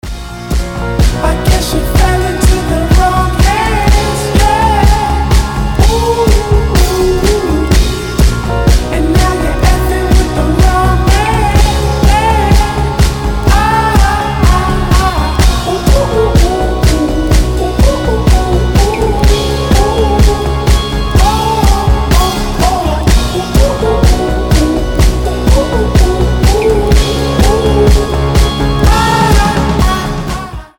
• Качество: 320, Stereo
ритмичные
RnB
indie pop
озорные
psychedelic
Neo Soul